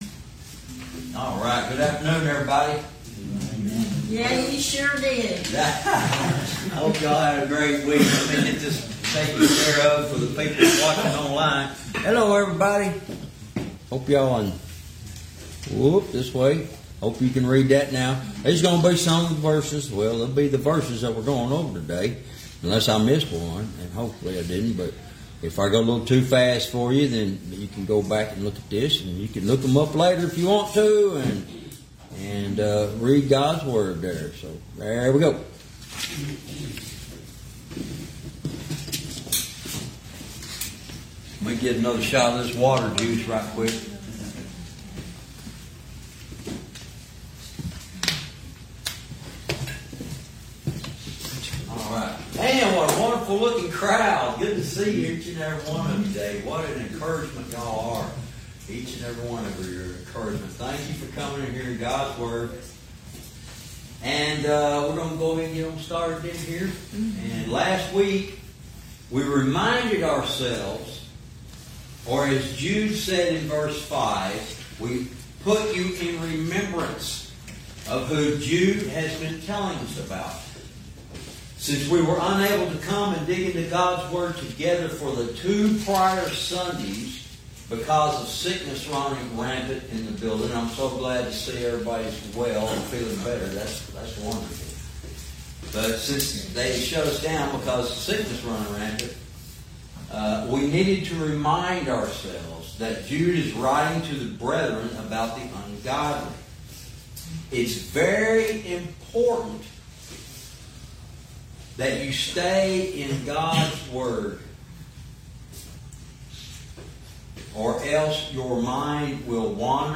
Verse by verse teaching - Lesson 50 verse 12